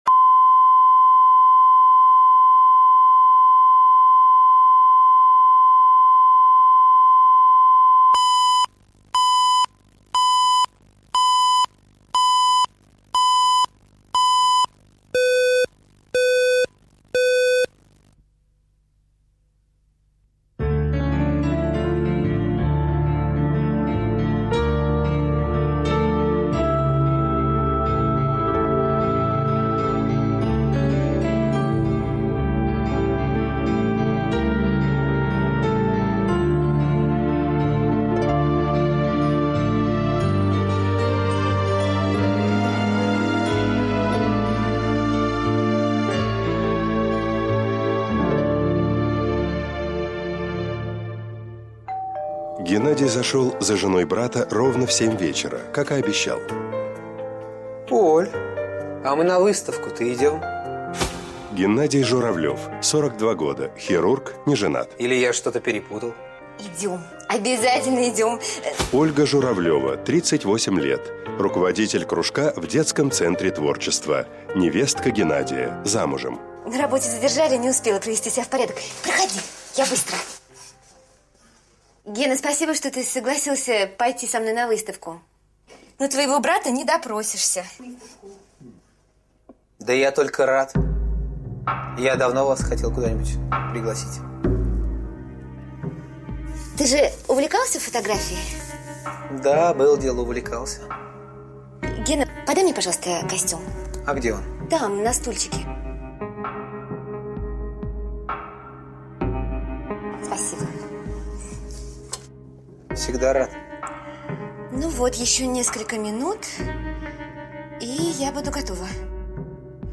Аудиокнига Брат за брата | Библиотека аудиокниг